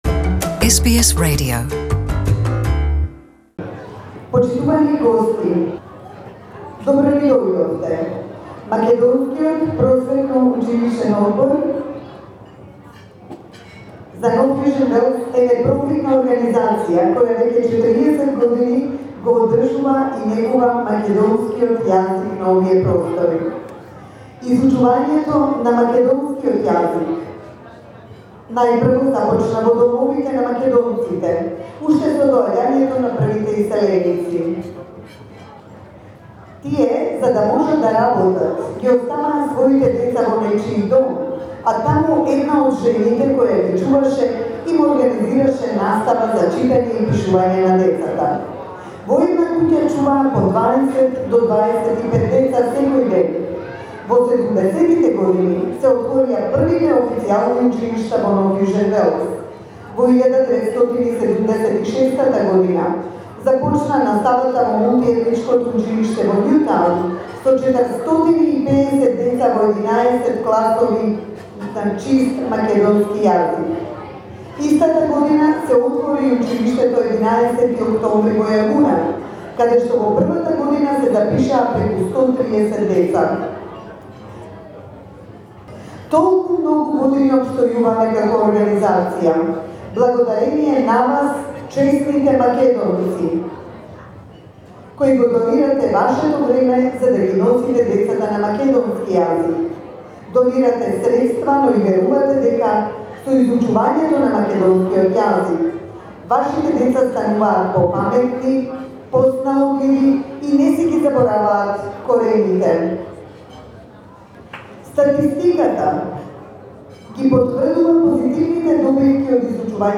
The Macedonian School Council for New South Wales is celebrating its 40th anniversary, since it was founded back in the 70's. The first official Macedonian language classes were established in Australia in 1976, hosting as many as 450 students who were attending the classes at that time. During the celebratory dinner on Friday night
in her speech